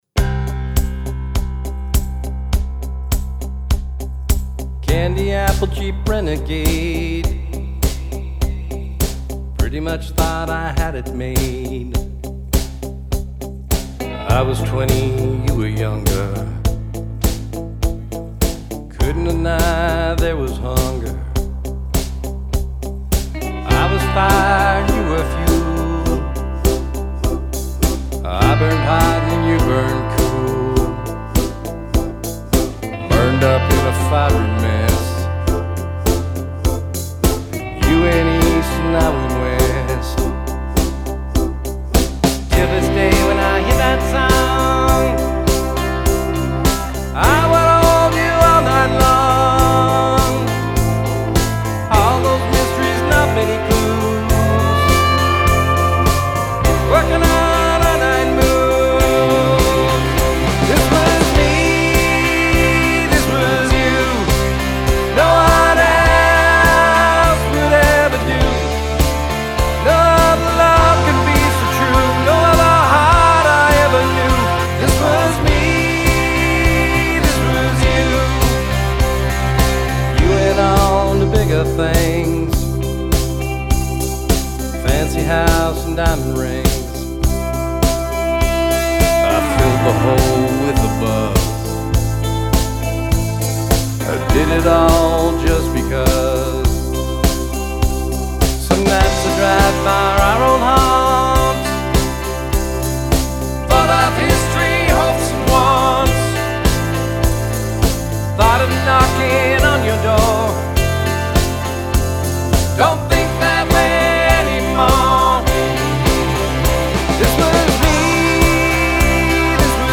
Mastering